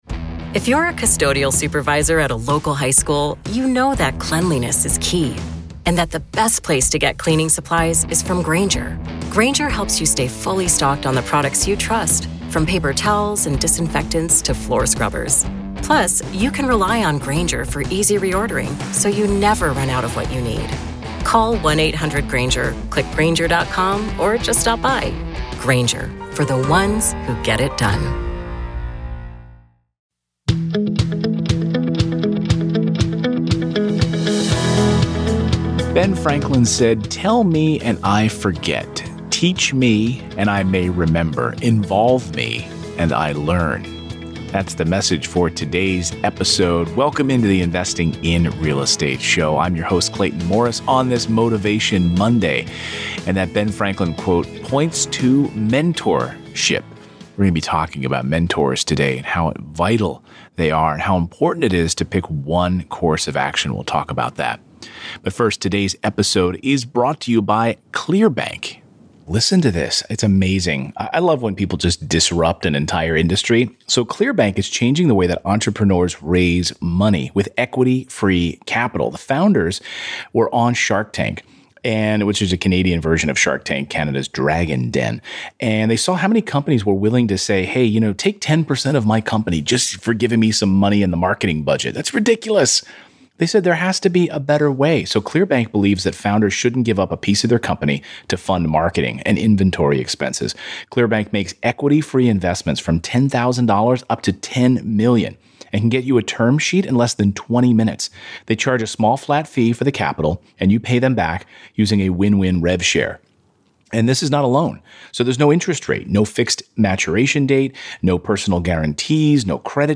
On this episode of the Investing in Real Estate Podcast, I’m sharing a conversation I had with one of our Financial Freedom Academy members. You’ll learn about the importance of following one path to success, how to find a mentor that resonates with you, and the benefits of working with a mentor.